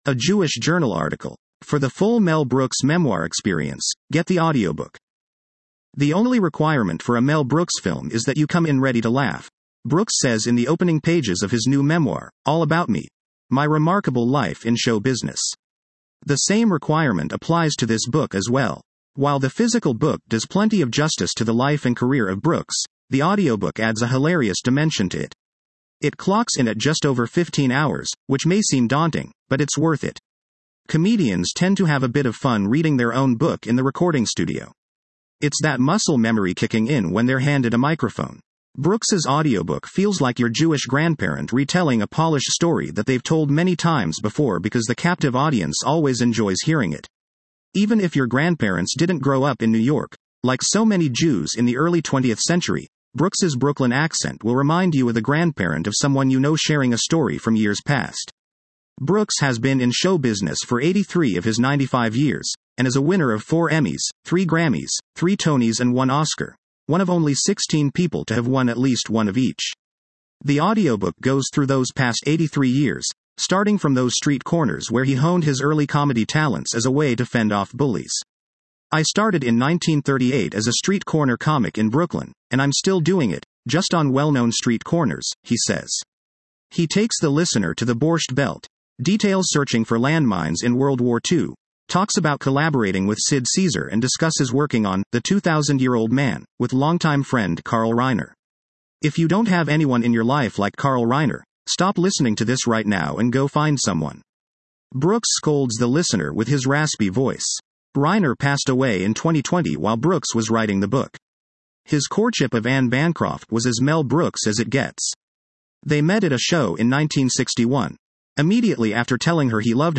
Comedians tend to have a bit of fun reading their own book in the recording studio.
Even if your grandparents didn’t grow up in New York, like so many Jews in the early 20th century, Brooks’ Brooklyn accent will remind you of the grandparent of someone you know sharing a story from years past.
“If you don’t have anyone in your life like Carl Reiner, stop listening to this right now and go find someone!” Brooks scolds the listener with his raspy voice.
He imitates the voices of the characters and sings the parts meant to be sung throughout the audiobook.